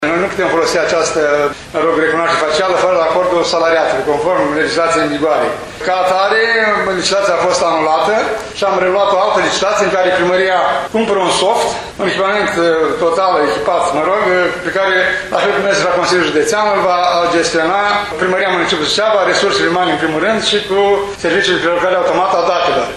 Primarul ION LUNGU a declarat că, în aceste condiții, administrația locală va organiza o nouă licitație pentru achiziționarea unui soft care va permite confirmarea prezenței angajaților pe bază de cartelă.